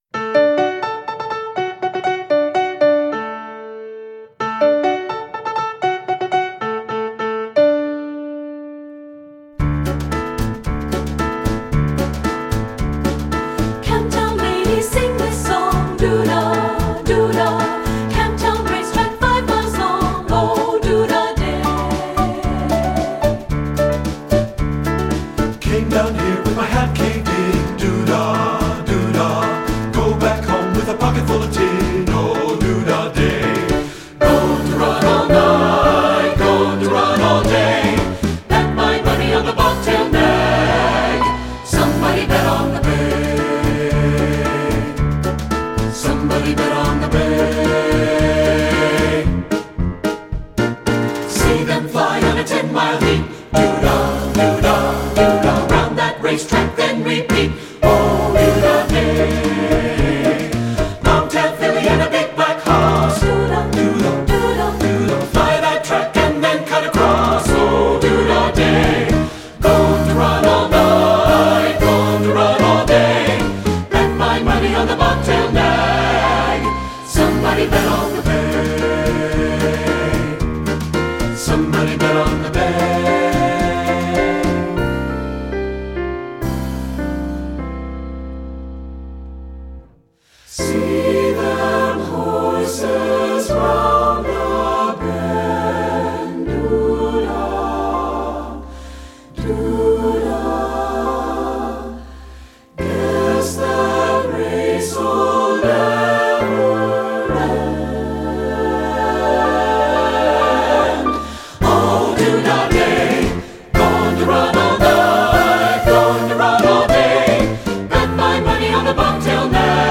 secular choral
SATB recording